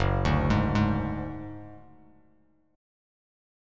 F#sus2#5 Chord